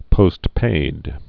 (pōstpād)